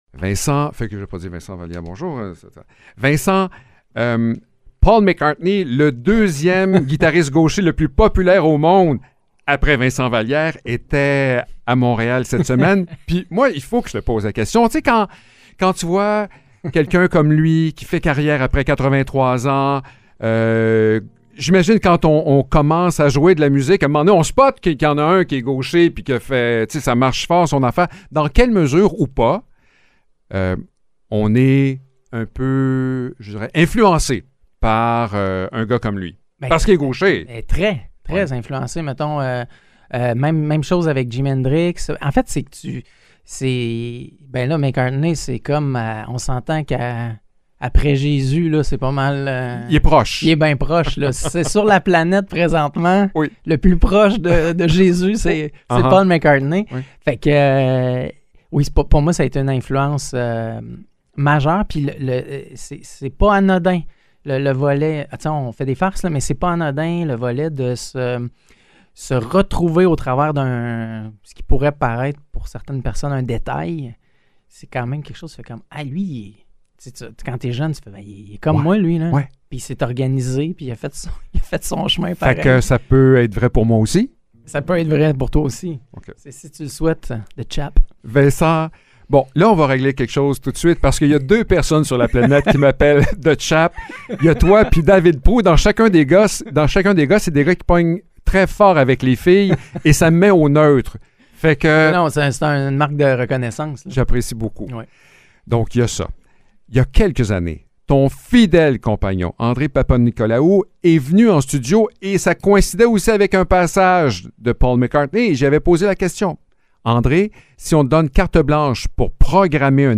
Entrevue avec Vincent Vallières directement de nos studios pour nous parler de son nouvel album Les saisons, les secondes.
entrevue-web-vincent-vallieres.mp3